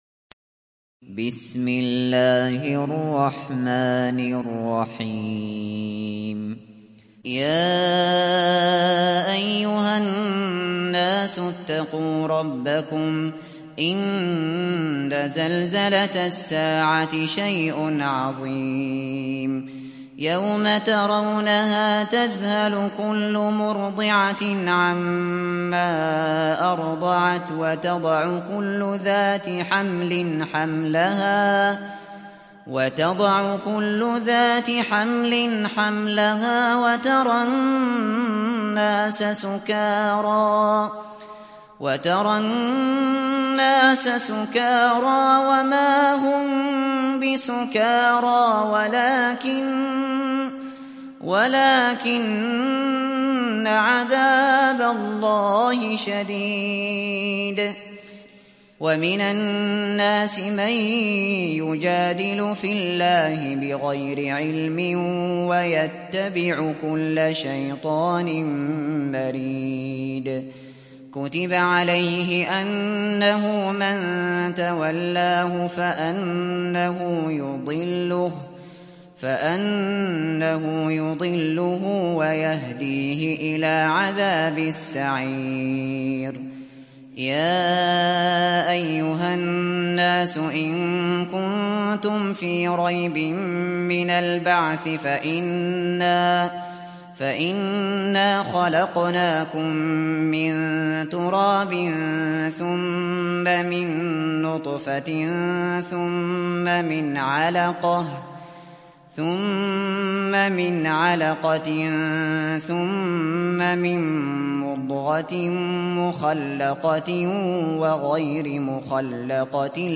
Hac Suresi İndir mp3 Abu Bakr Al Shatri Riwayat Hafs an Asim, Kurani indirin ve mp3 tam doğrudan bağlantılar dinle